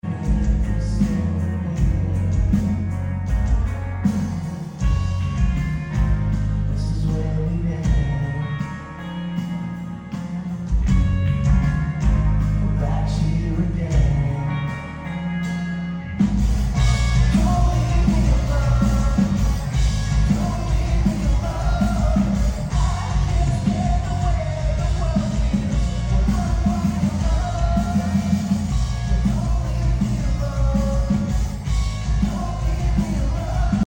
at the Orlando stop